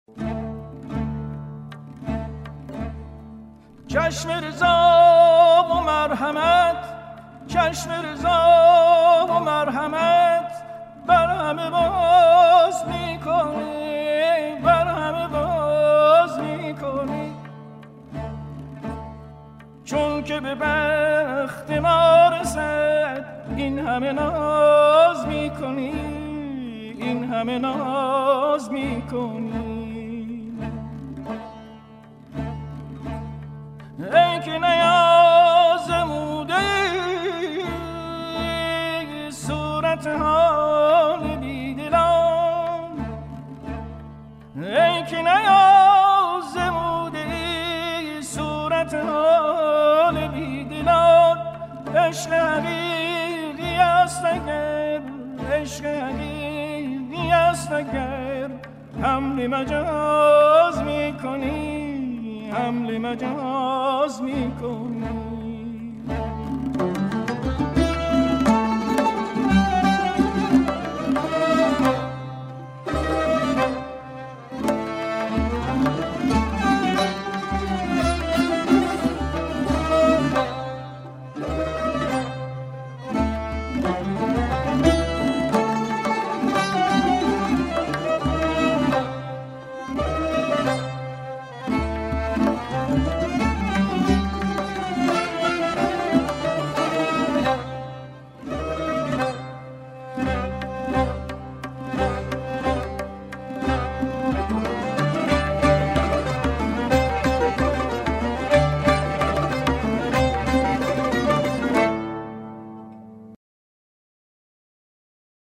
سبک : سنتی